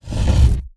Rotate Stone 03.wav